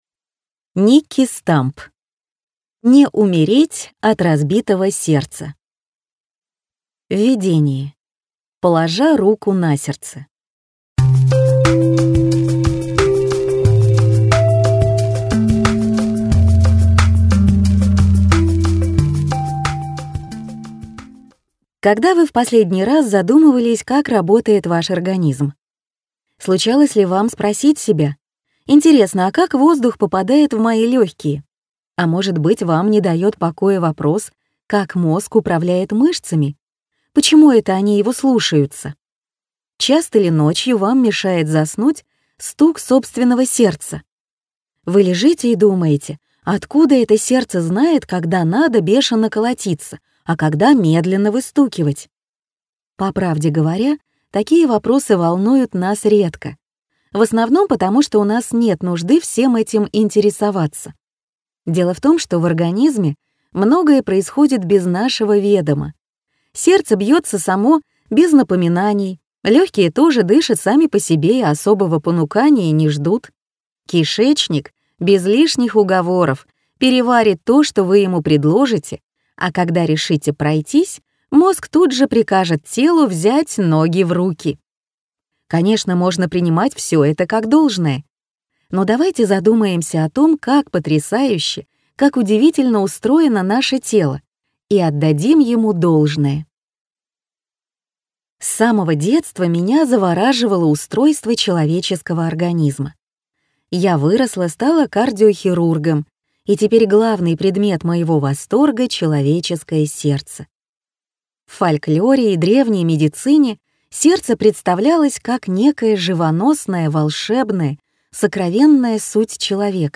Аудиокнига (Не) умереть от разбитого сердца | Библиотека аудиокниг